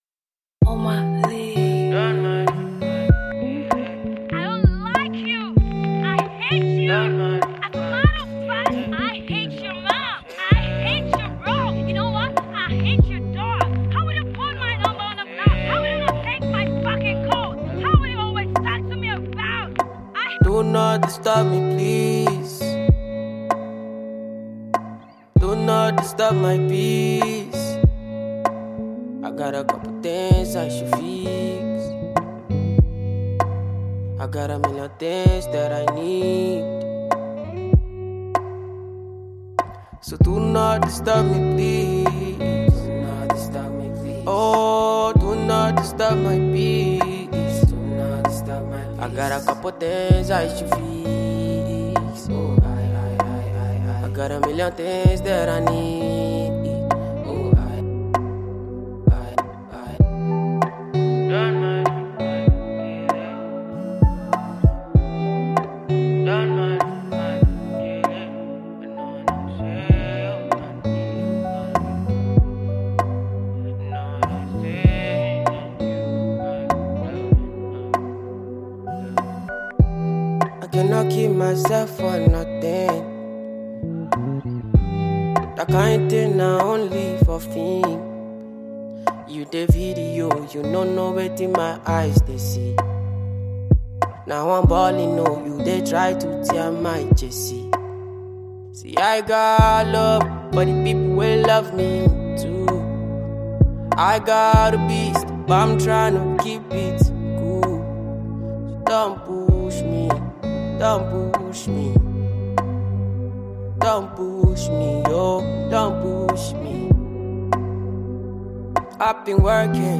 is a slow tempo cool track and a potential hit vibe.
• Genre: Afro-beats